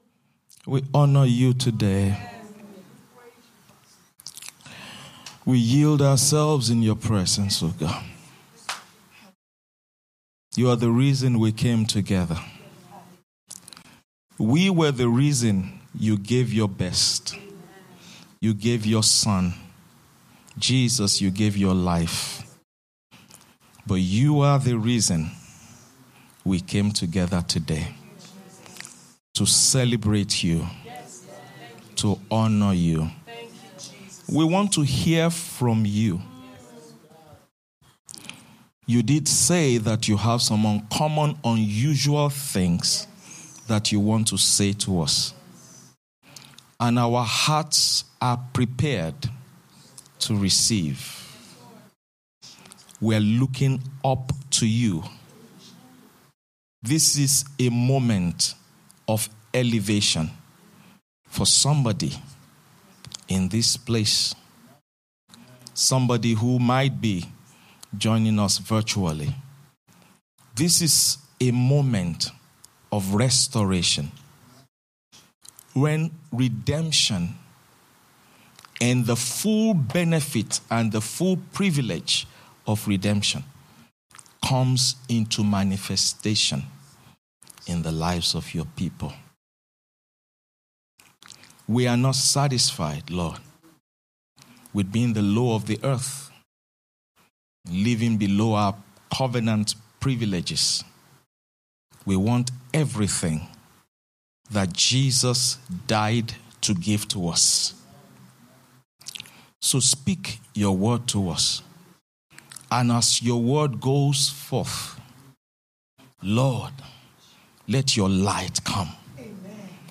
Resurrection Sunday service